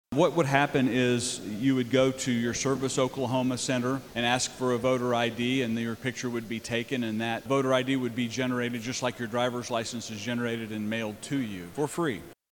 CLICK HERE to listen to commentary from Representative Terry O’Donnell.